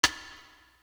Feel Me Perc.wav